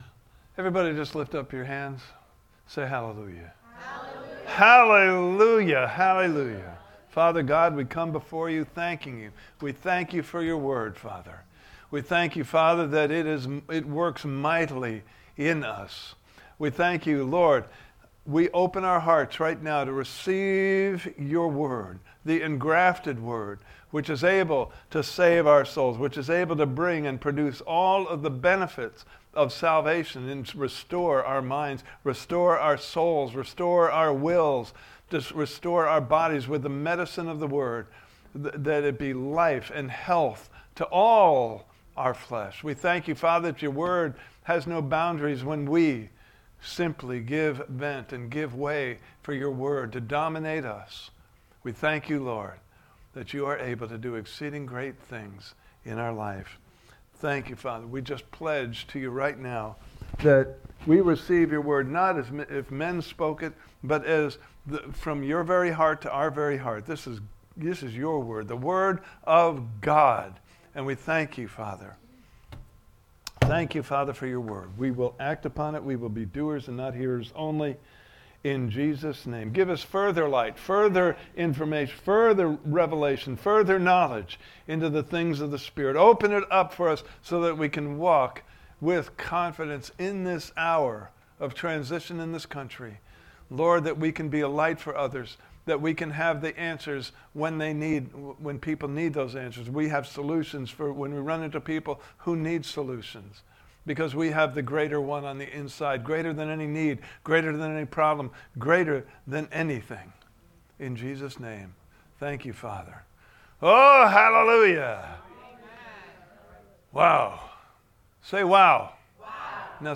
Series: Walking With the One Who Knows the Future Service Type: Sunday Morning Service « Part 2